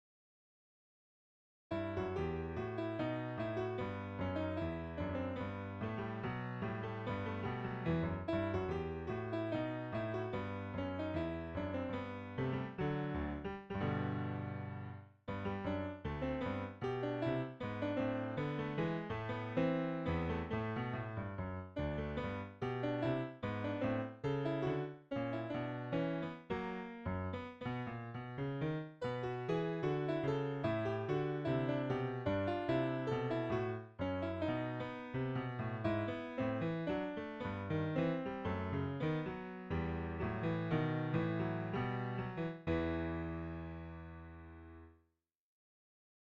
I started playing it again with a cheap Yamaha electric piano and using an app like Piano Marvel to test my sight-reading skills.